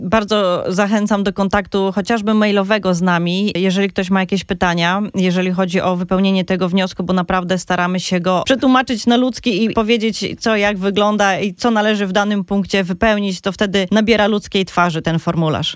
O tym jak zdobyć polski dokument i dlaczego jest on ważny, wyjaśnia w studiu Radia Deon konsul Bernadetta Pałka-Maciejewska, z Konsulatu Generalnego RP w Chicago, szefowa wydziału paszportowego.